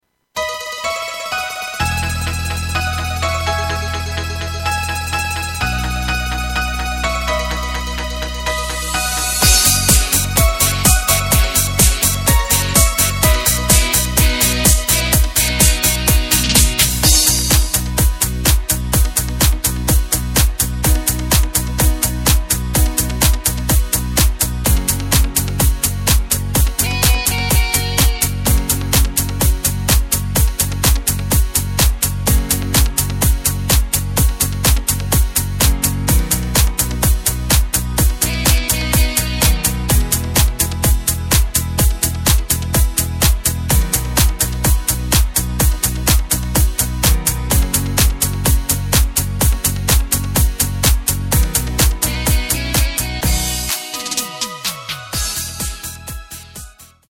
Tempo:         126.00
Tonart:            B
Discofox aus dem Jahr 2013!